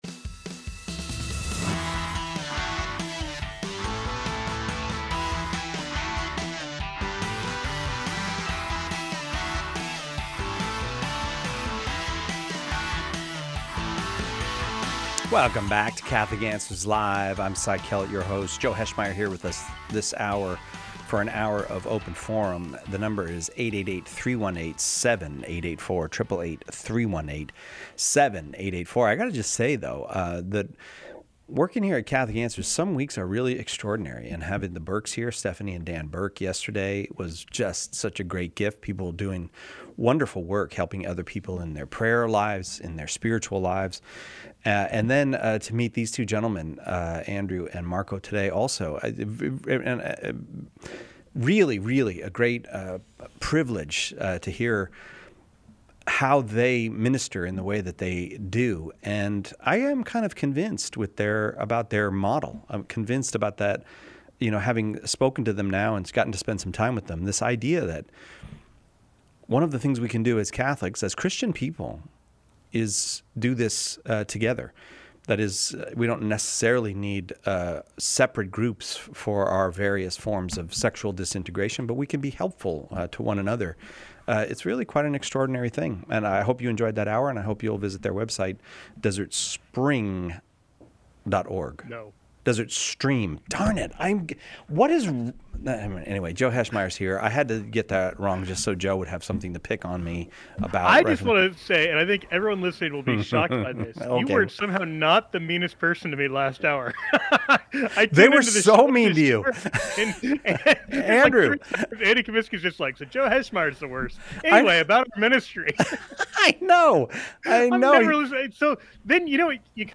Open Forum – Questions Covered: 07:34